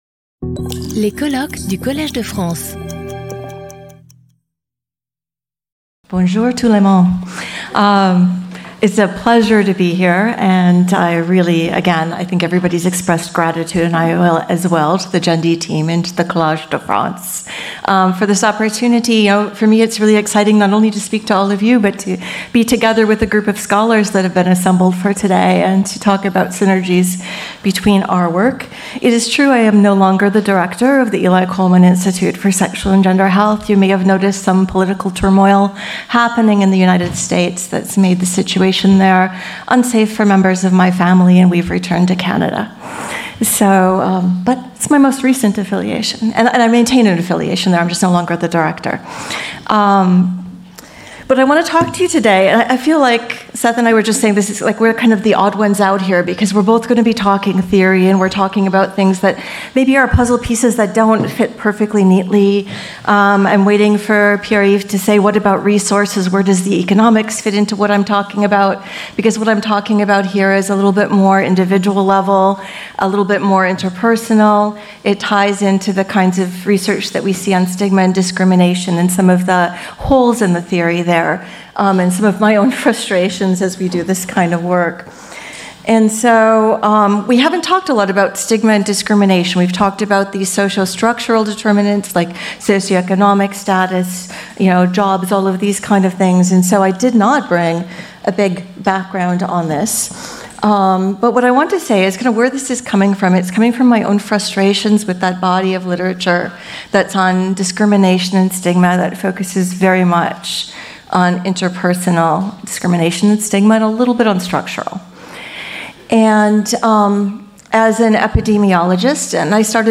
Skip youtube video player Listen to audio Download audio Audio recording This video is offered in a version dubbed in French.